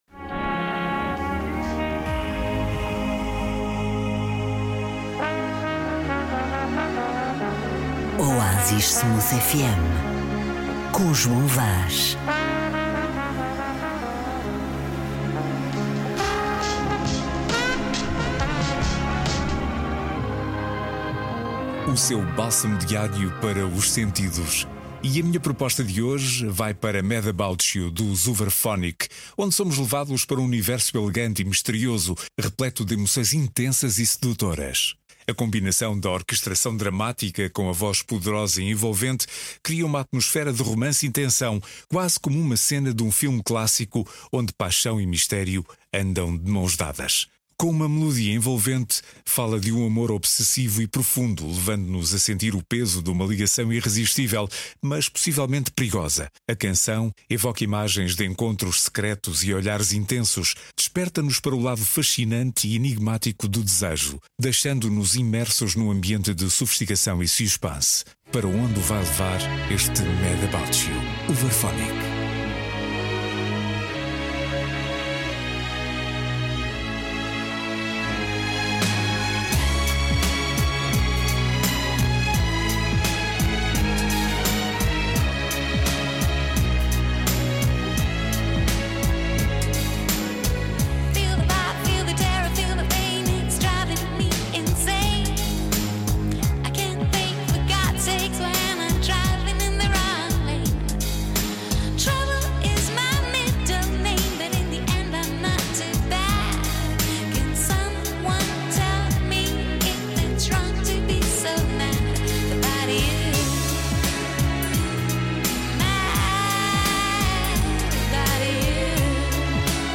Viajamos para um universo elegante e misterioso, repleto de emoções intensas e sedutoras.